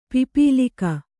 ♪ pipīlika